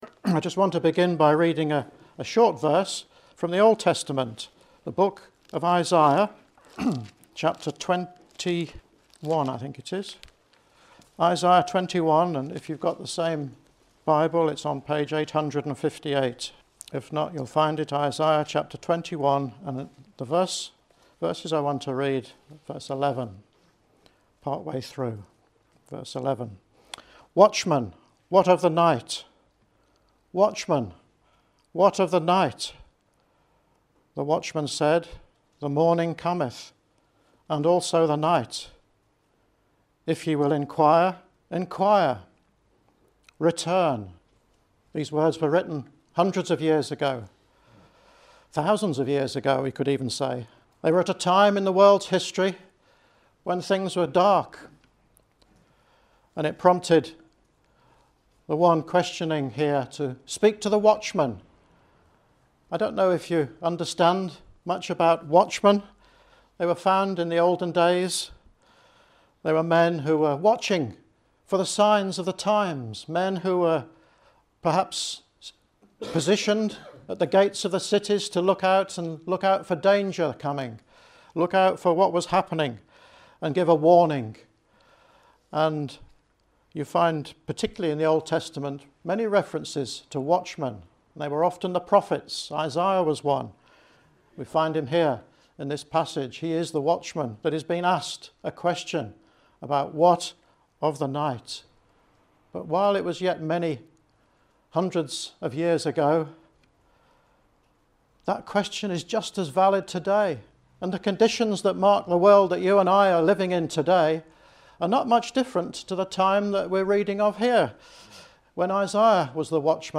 Come and hear more as you listen to this Gospel message.